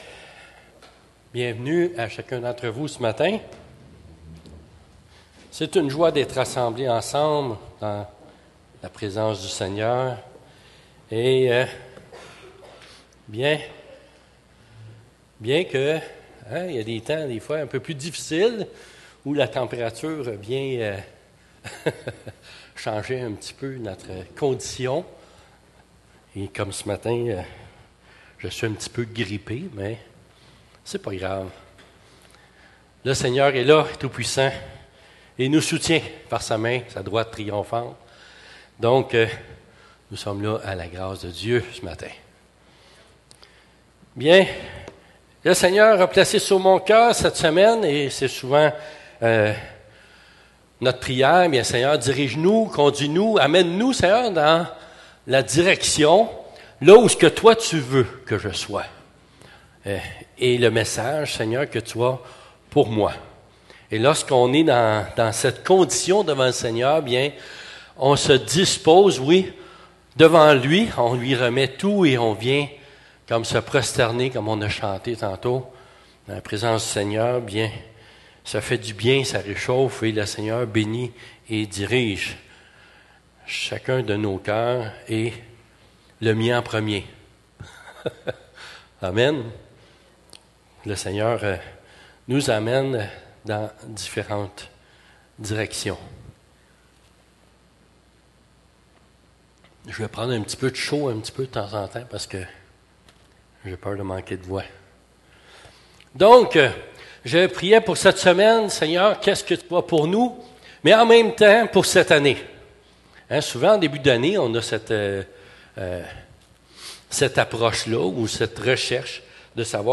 18 janvier 2026 ← Prédication précédent Prédication suivant →